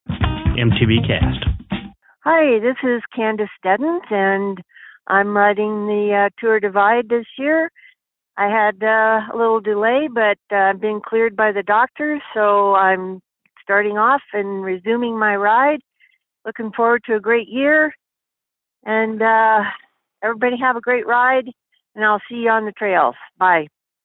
Posted in Calls , TD18 Tagged bikepacking , cycling , MTBCast , TD18 , ultrasport permalink